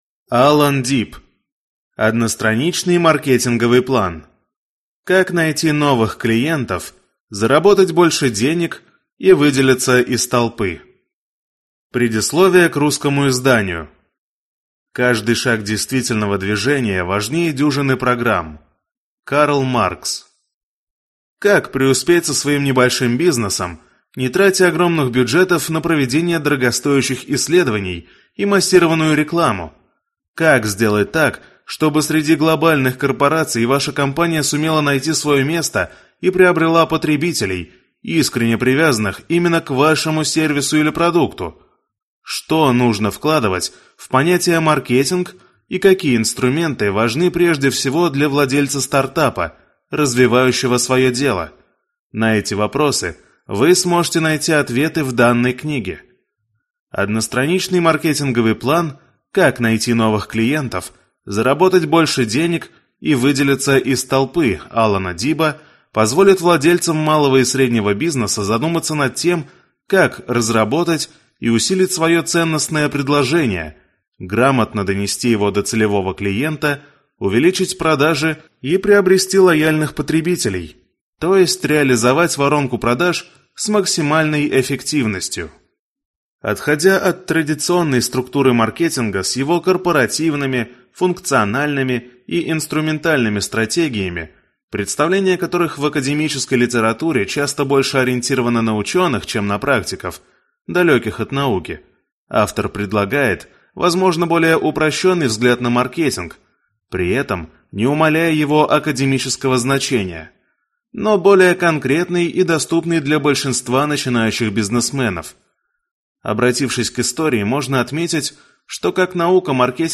Аудиокнига Одностраничный маркетинговый план. Как найти новых клиентов, заработать больше денег и выделиться из толпы | Библиотека аудиокниг